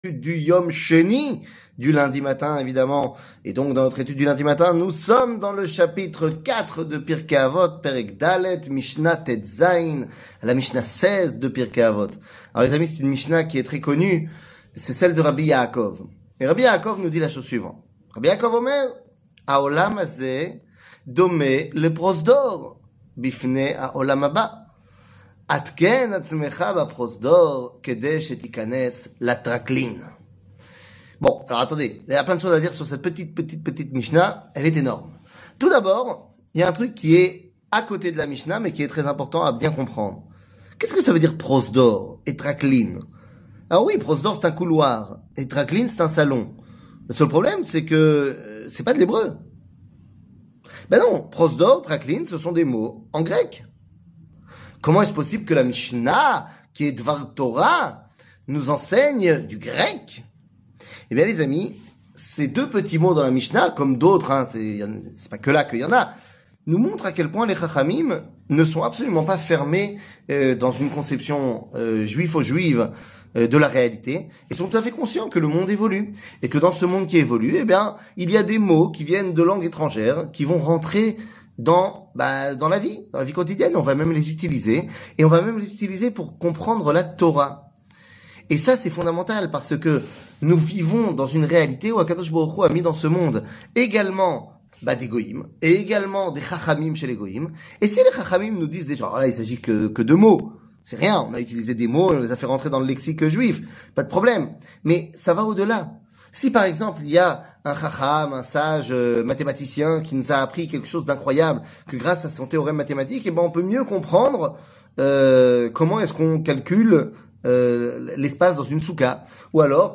שיעור מ 21 אוגוסט 2023
שיעורים קצרים